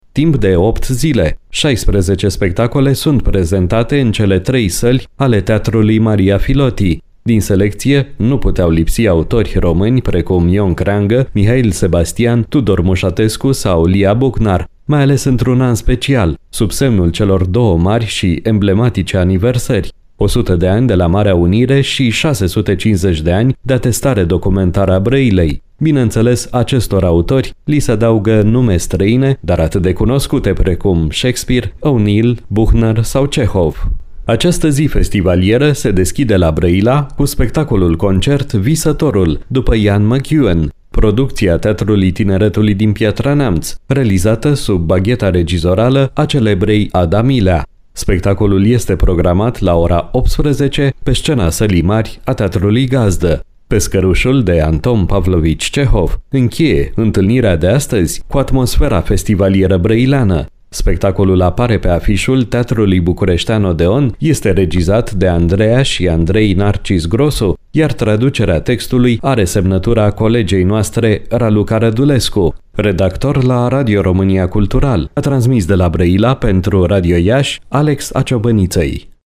se află la Brăila și ne oferă amănunte despre eveniment: